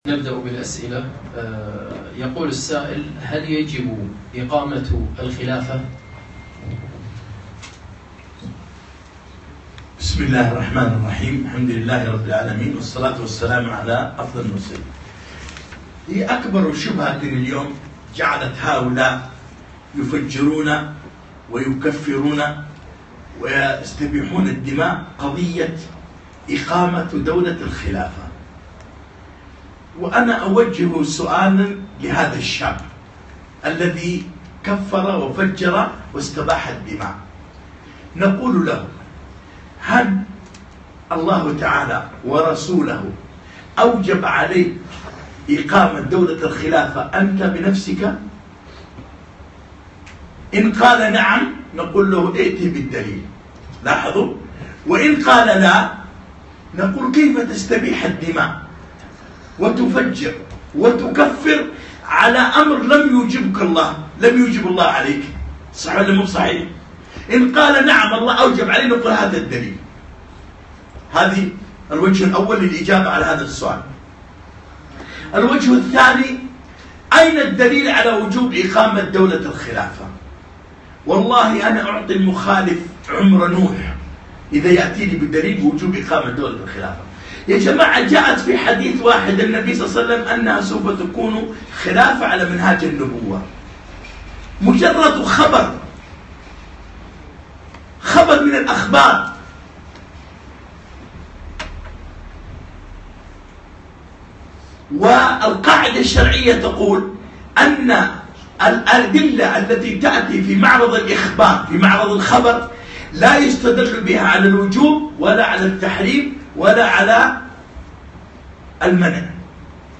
أسئلة - بعد محاضرة أصول الخوارج المتقدمين